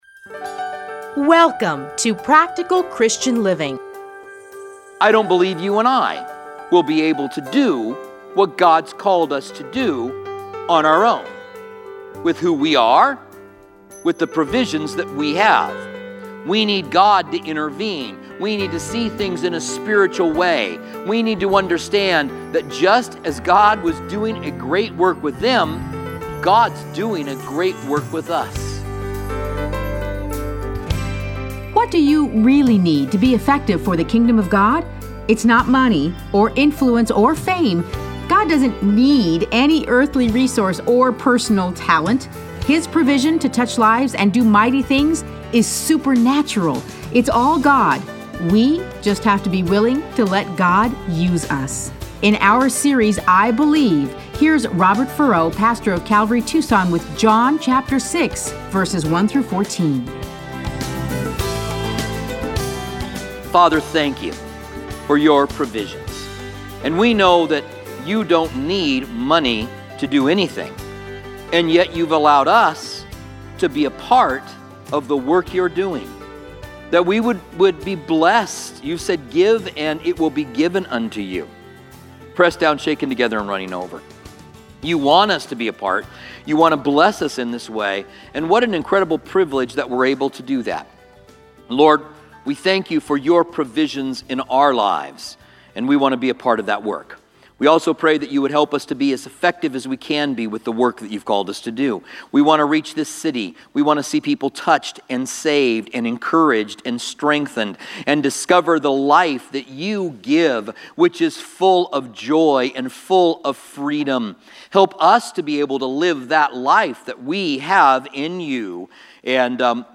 Listen to a teaching from John 6:1-14.